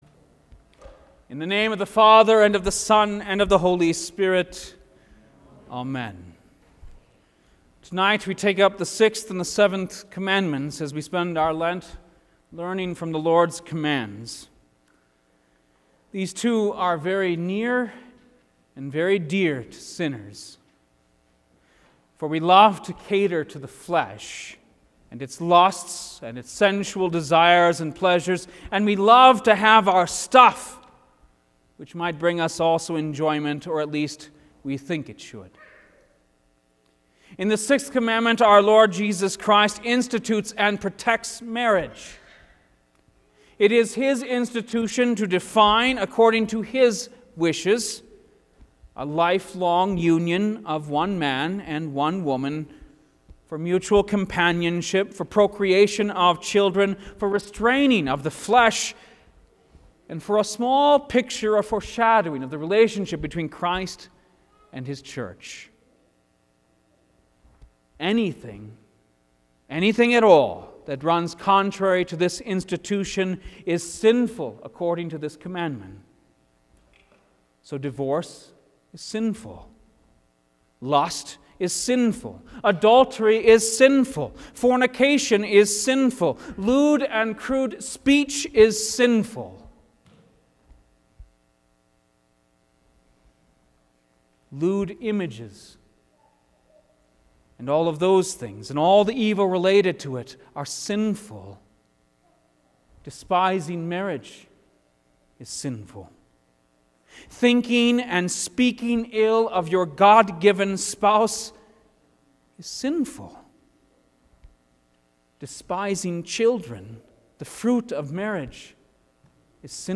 Lenten Midweek Service Four